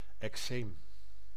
Ääntäminen
IPA: /ɛkˈseːm/